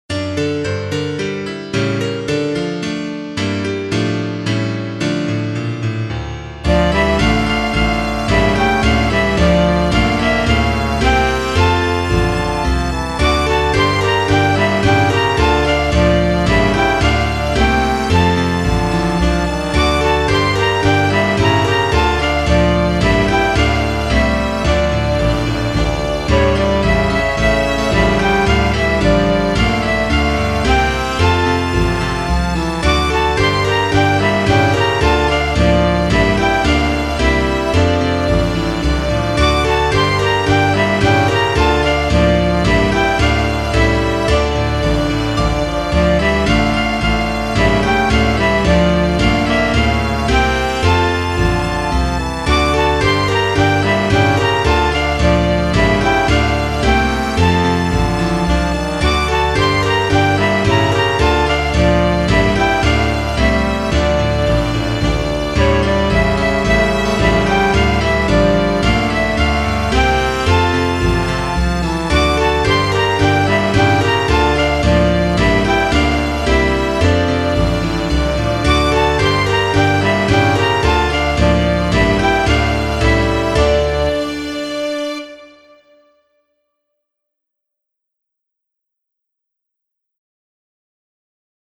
Greensleeves Popolare inglese Scarica file Stampa Email Yankee doodle Popolare americano Scarica file Stampa Email C'era un re Canone Scarica file Stampa Email Oyfn Pripetshik Klezmer Scarica file Stampa Email